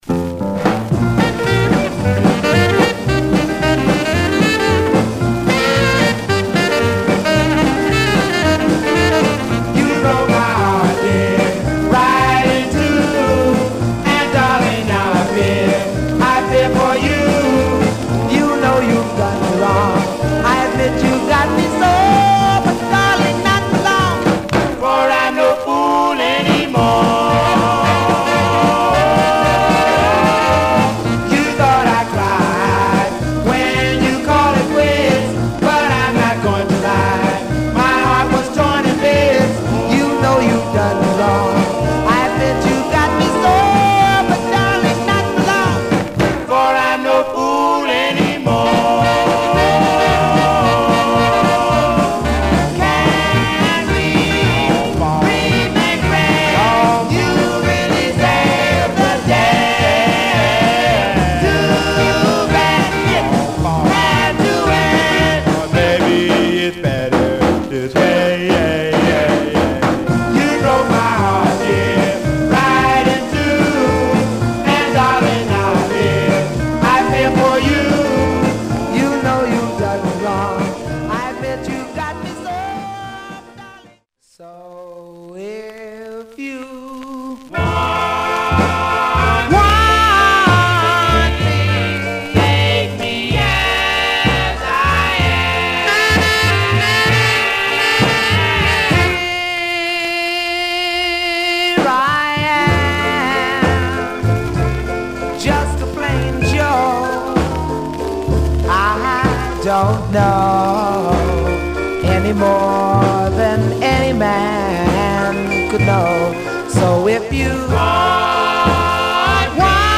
Surface noise/wear
Mono
Male Black Groups